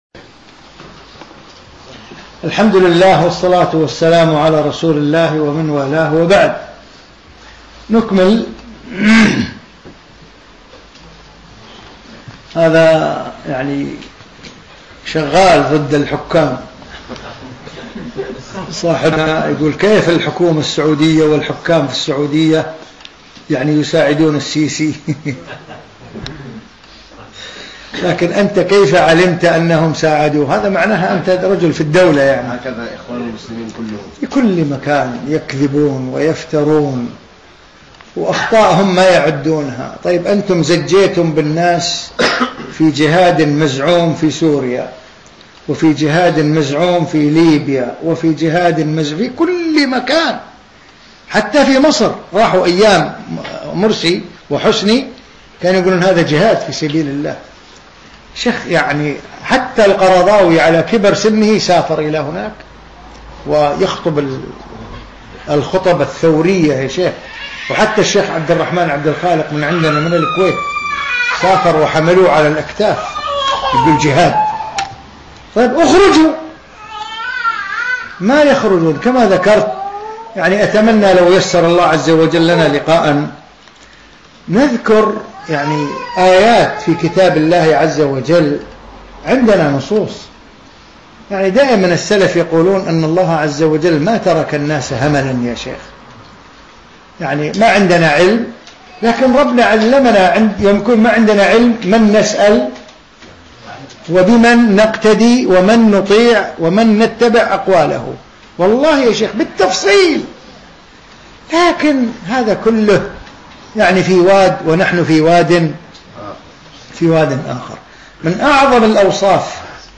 في مسجد الصحابة السلفي في مدينة برمنجهام البريطانية في 20 شوال 1435